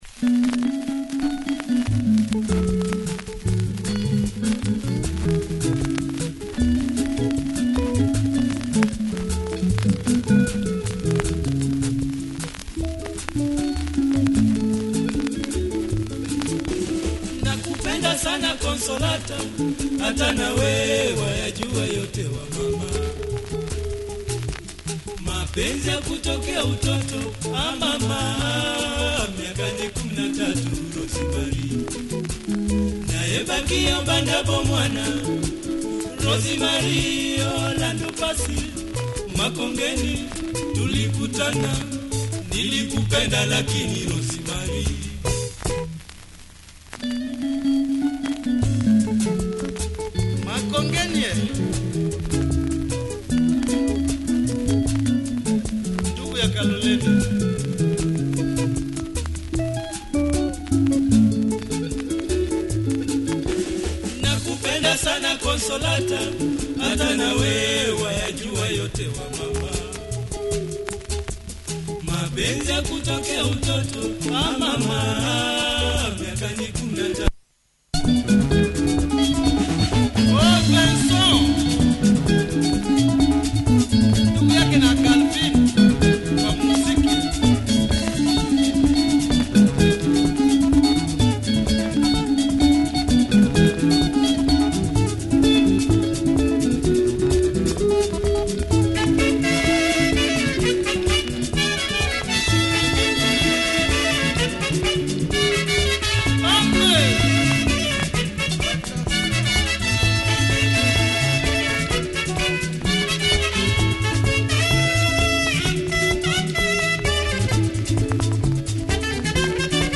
sax
Disc has some wear, no deep marks and plays fine.